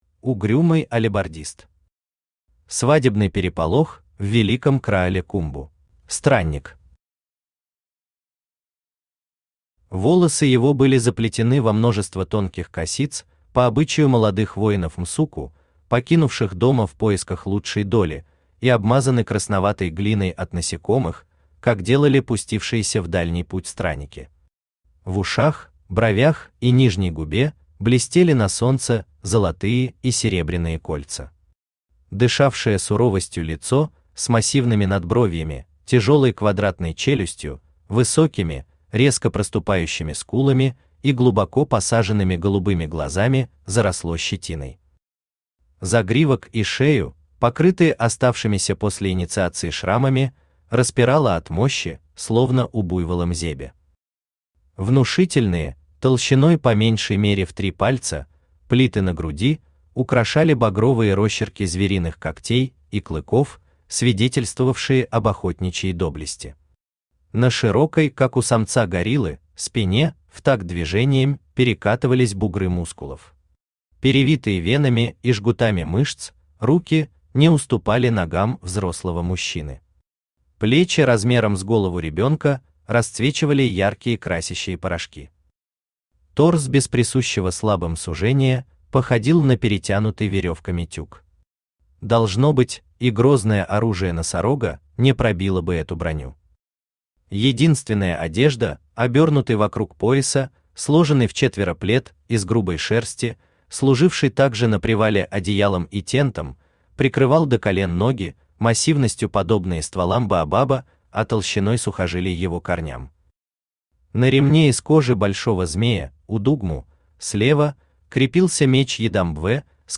Aудиокнига Свадебный переполох в Великом Краале Кумбу Автор Угрюмый Алебардист Читает аудиокнигу Авточтец ЛитРес.